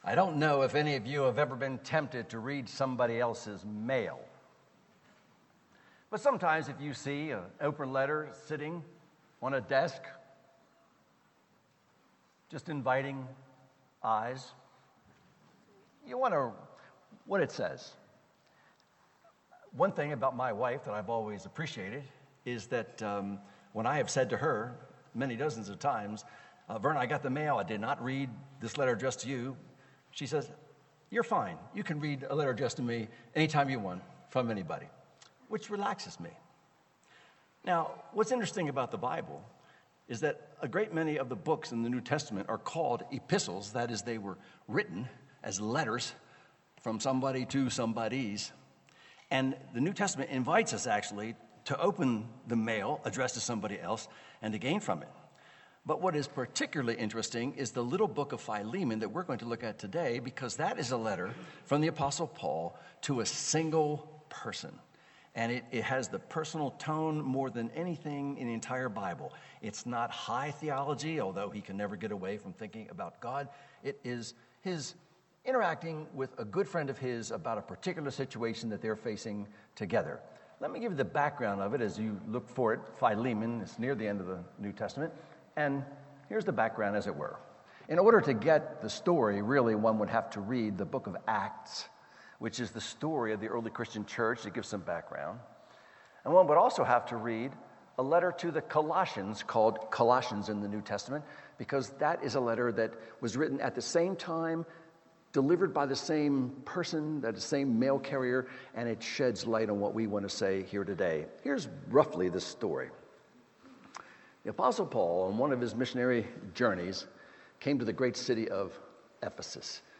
Philemon — Audio Sermons — Brick Lane Community Church